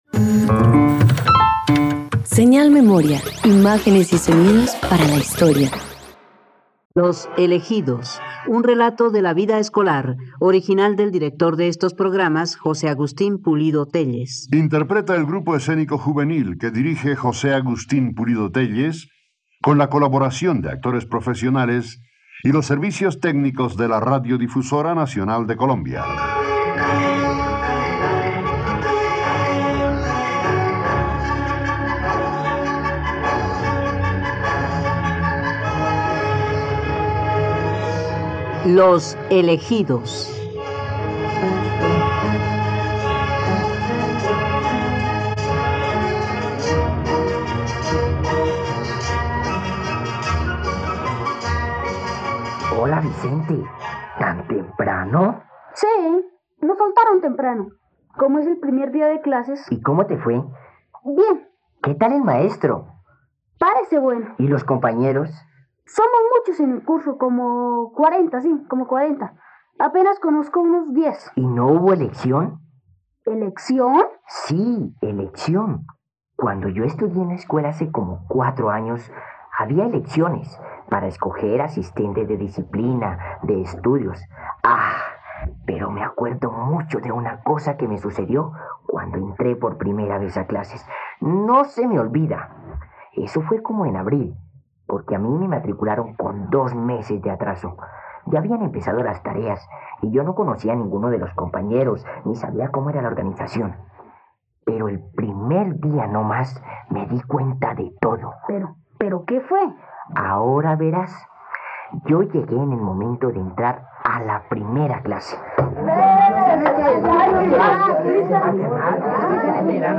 ..Radioteatro. Escucha la obra ‘Los elegidos’ del destacado escritor colombiano José Agustín Pulido Téllez en la plataforma de streaming RTVCPlay.